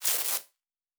pgs/Assets/Audio/Sci-Fi Sounds/Electric/Spark 09.wav at master
Spark 09.wav